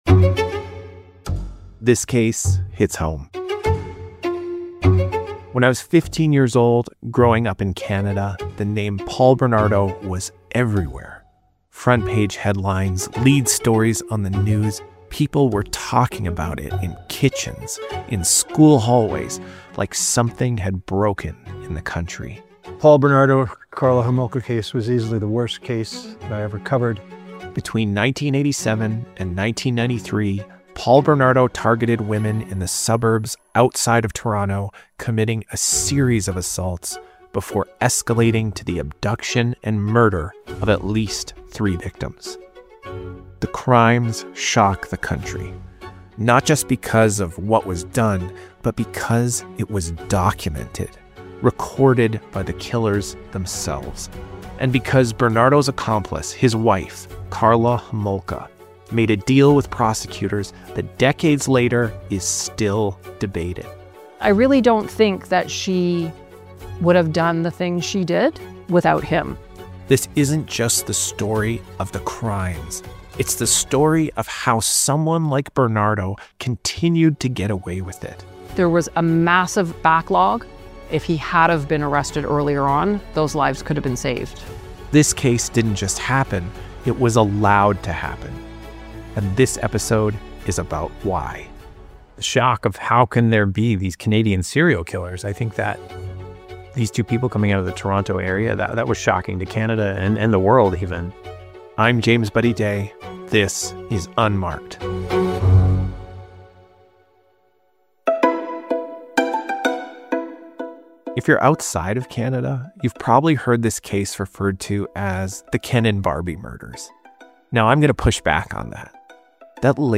In this episode of UNMARKED, we traveled to Toronto and speak with journalists who covered the case in real time.